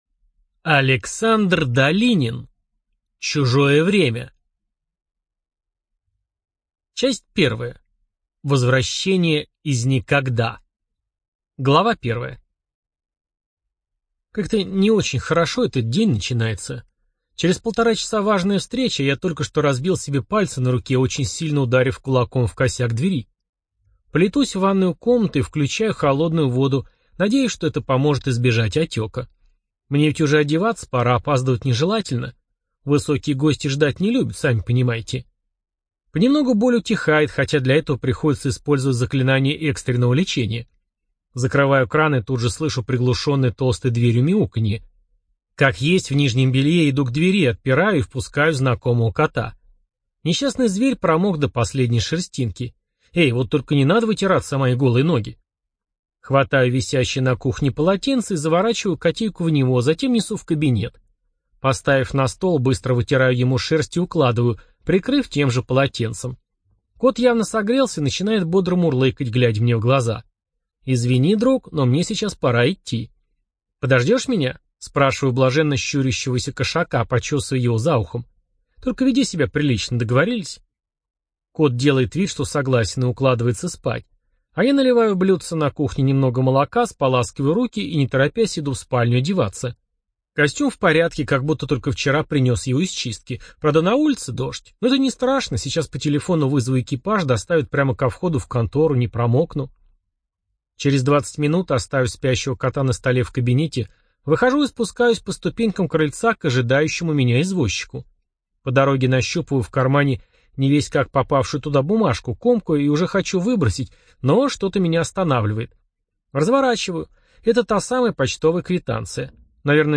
Жанр: Городское фэнтези